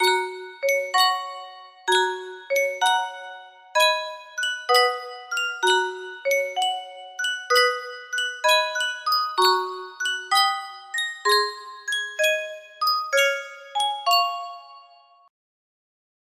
Sankyo Music Box - Sidewalks of New York MOG music box melody
Full range 60